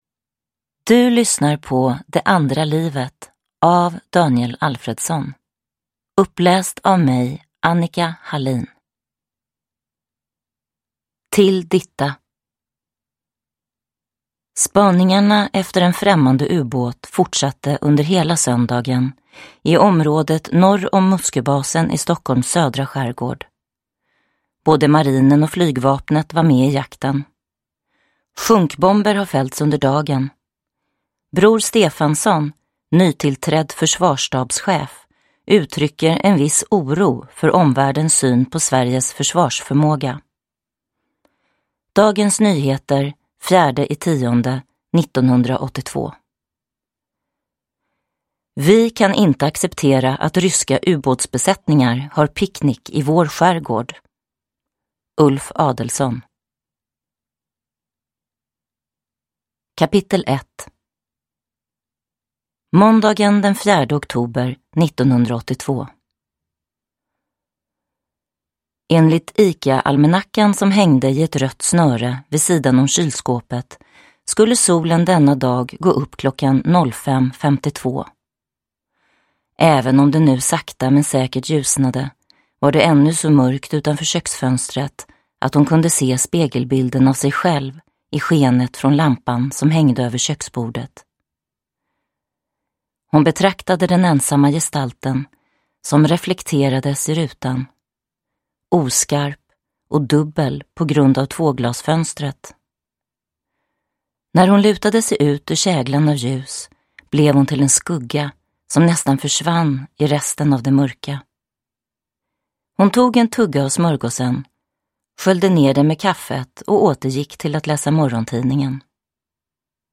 Uppläsare: Annika Hallin
Ljudbok